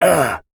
Male_Grunt_Hit_19.wav